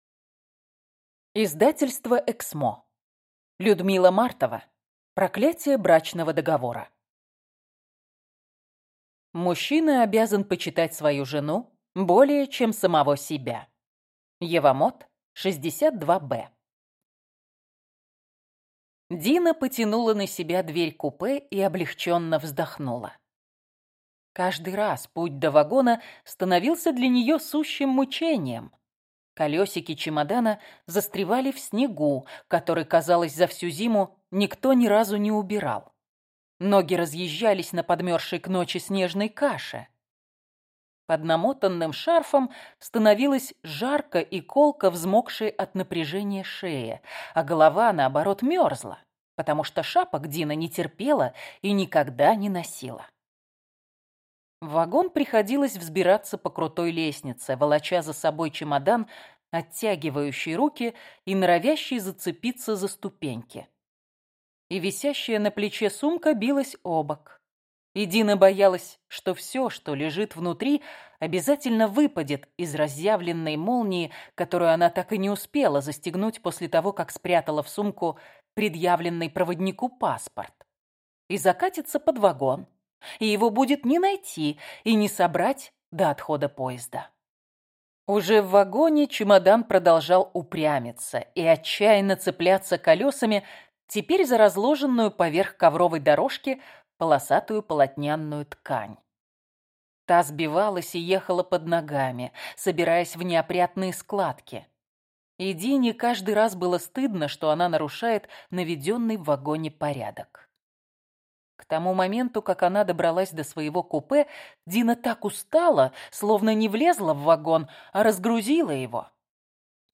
Аудиокнига Проклятие брачного договора | Библиотека аудиокниг